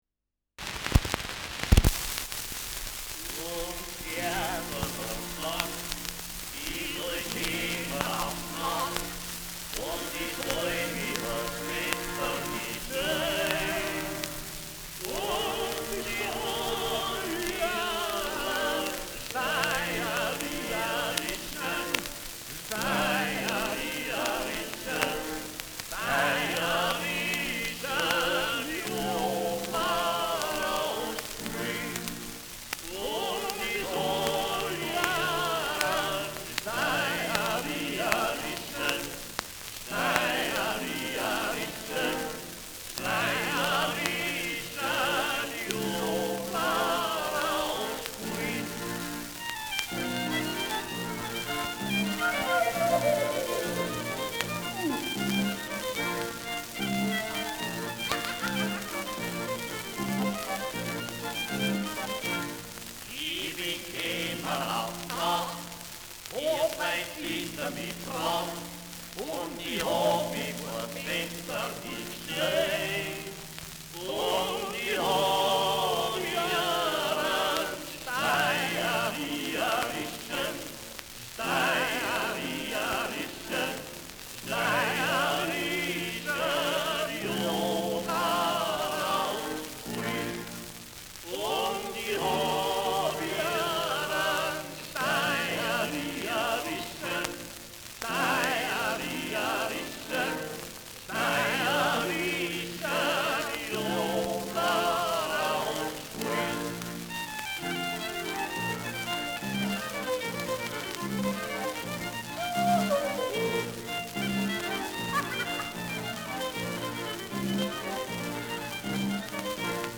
Schellackplatte
präsentes Rauschen : Knistern
Schrammel-Trio, München [K/L/P] (Interpretation)